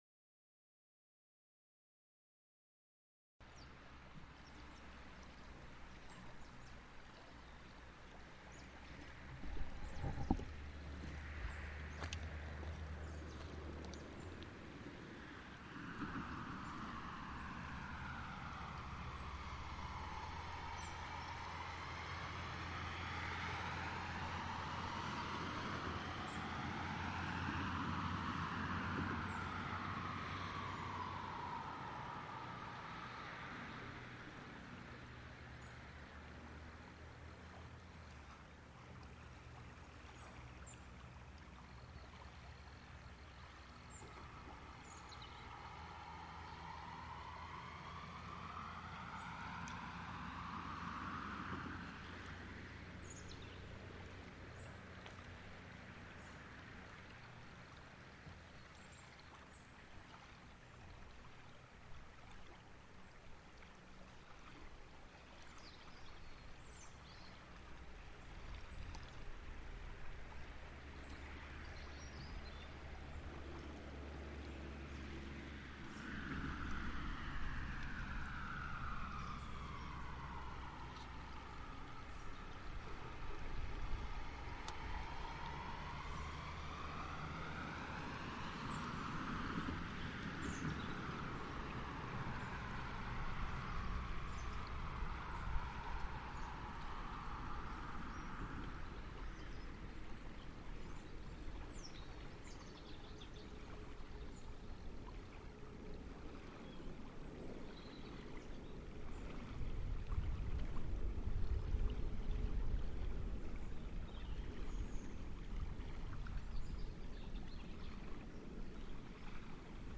Bruit de l'eau sous le pont de Gluges